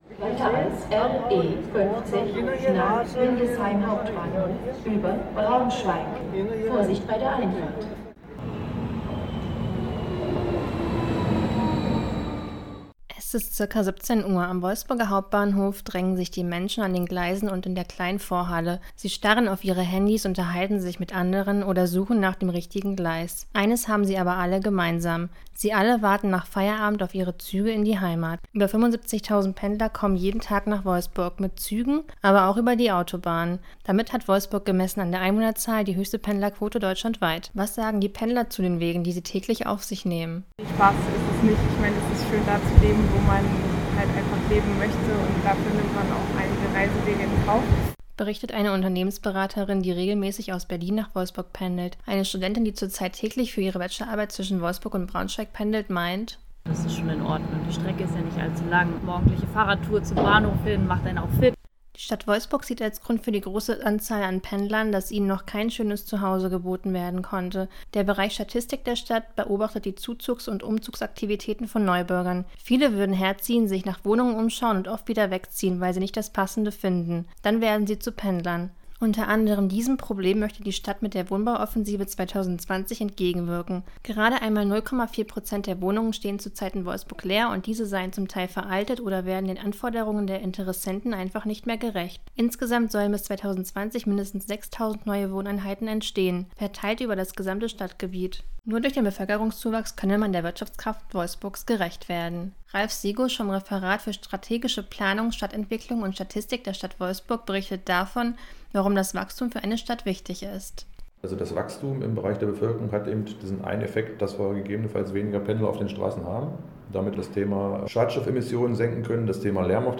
Wolfsburg ist eine Pendlerstadt: Rund 75.000 Menschen pendeln täglich in die VW-Stadt – mehr als die Hälfte der Einwohnerzahl. Woran liegt das und wie versucht die Stadtverwaltung dem entgegenzuwirken? Campus38 auf der Suche nach Antworten im Feierabendverkehr am Wolfsburger Hauptbahnhof.